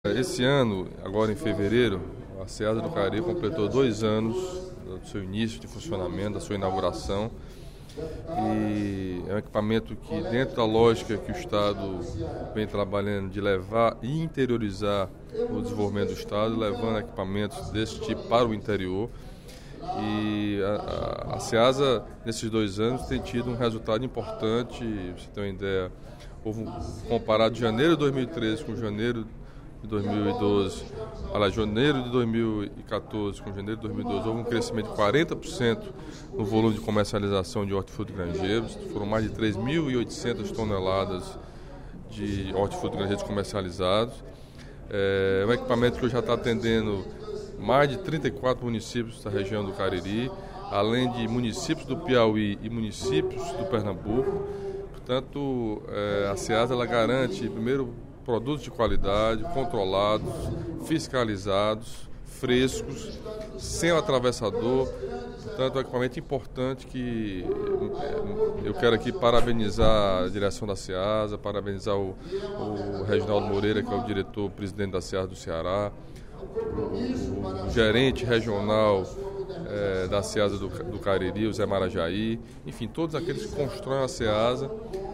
O deputado Camilo Santana (PT) comemorou, durante o primeiro expediente da sessão plenária desta sexta-feira (28/02), os dois anos da Ceasa de Barbalha.
Em aparte, o deputado Dr. Pierre (PCdoB) questionou em “qual vertente do programa o município de Uruburetama se encaixaria, visto que Umirim, município vizinho, foi contemplado com o Minha Casa, Minha Vida Rural e Uruburetama não”.